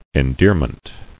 [en·dear·ment]